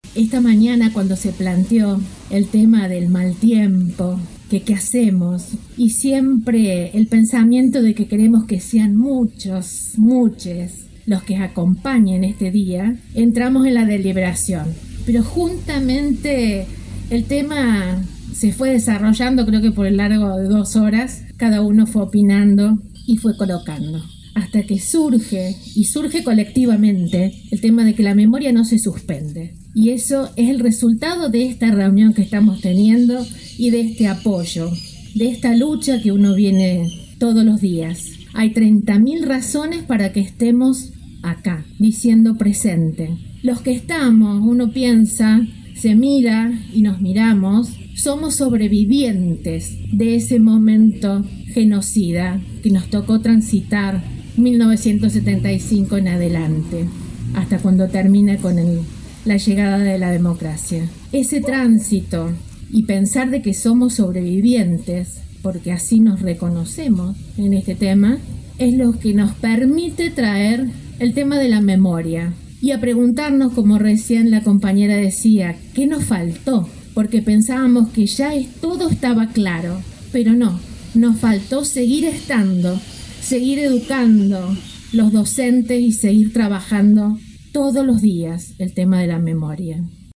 dijo durante su mensaje en plaza San Martín.